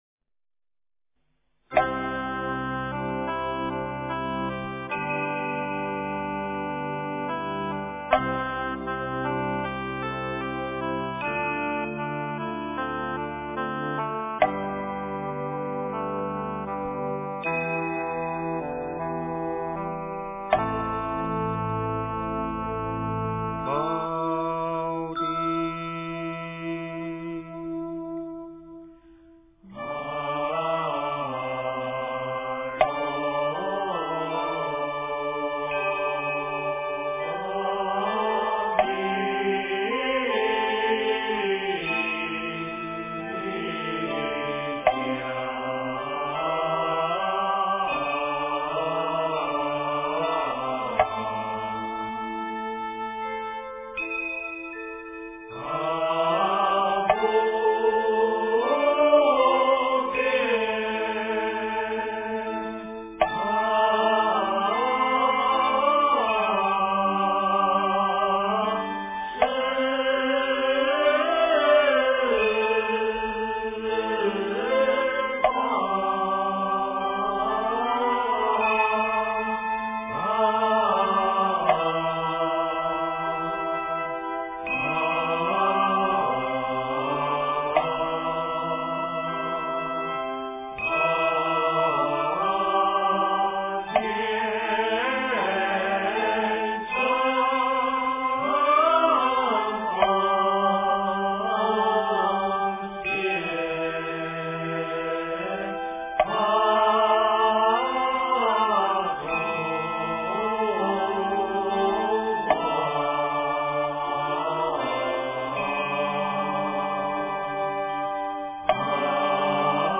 宝鼎祝福香赞--僧团 经忏 宝鼎祝福香赞--僧团 点我： 标签: 佛音 经忏 佛教音乐 返回列表 上一篇： 六字大明咒--佛光山梵呗团 下一篇： 一心求忏悔-闽南语--新韵传音 相关文章 弥陀赞 炉香赞 上师三宝真言--僧团 弥陀赞 炉香赞 上师三宝真言--僧团...